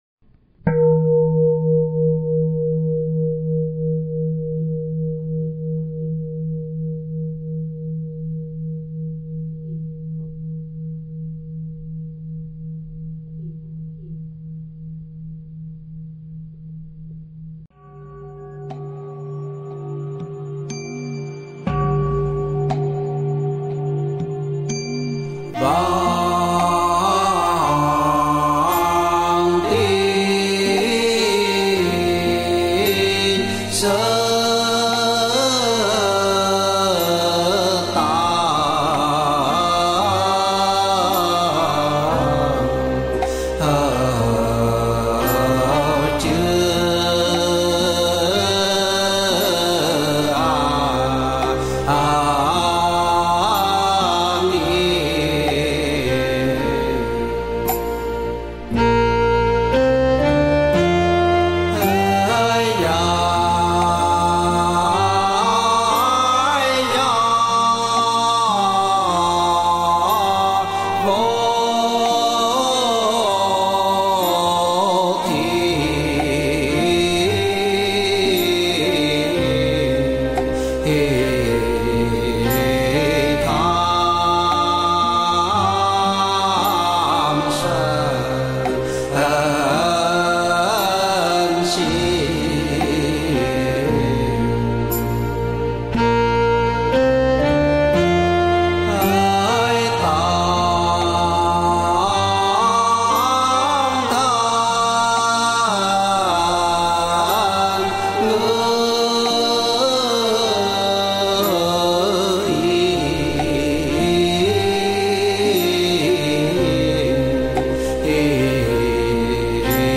Thể loại: Nhạc Niệm Phật